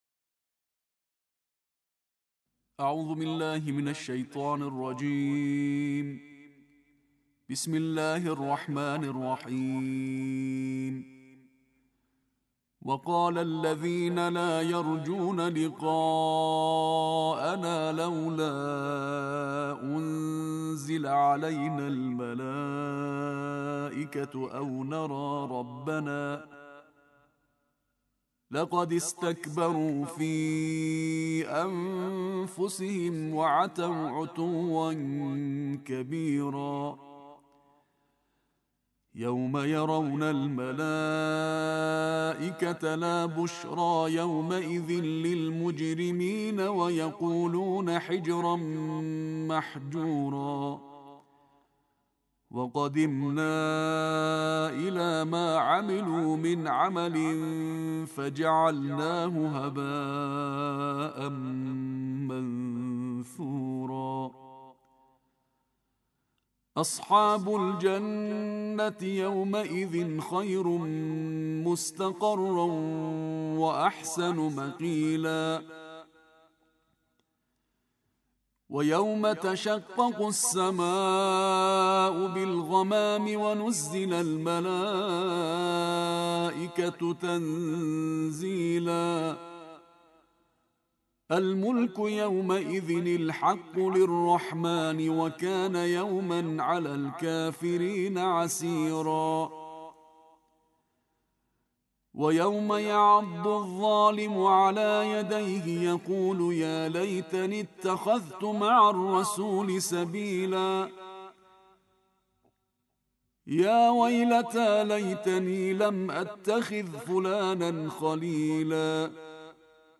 Bu tərtil ilk dəfə paylaşılır.